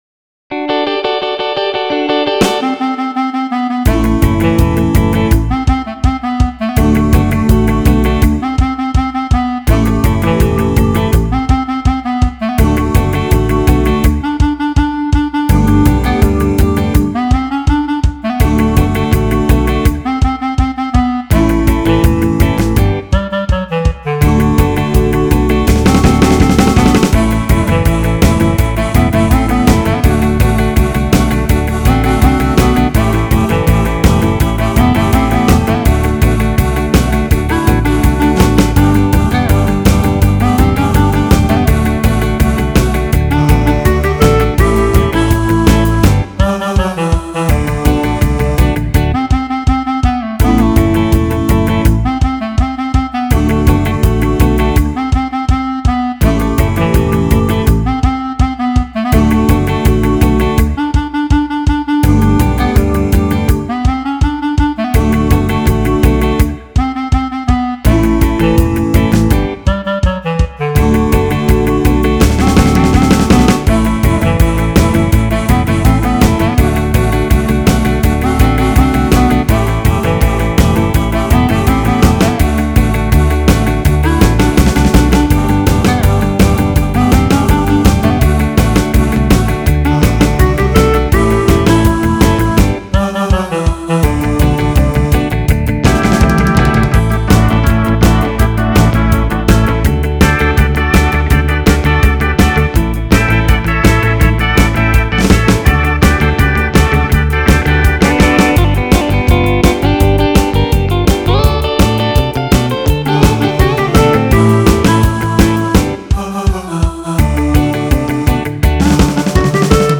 (Pop/Rock List)